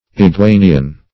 iguanian - definition of iguanian - synonyms, pronunciation, spelling from Free Dictionary Search Result for " iguanian" : The Collaborative International Dictionary of English v.0.48: Iguanian \I*gua"ni*an\, a. (Zool.)